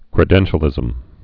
(krĭ-dĕnshə-lĭzəm)